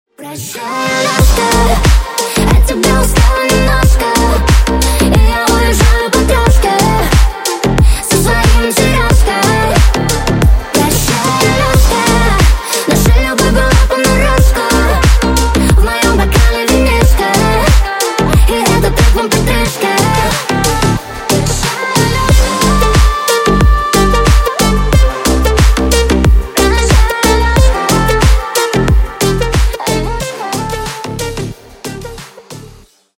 Клубные Рингтоны
Рингтоны Ремиксы » # Поп Рингтоны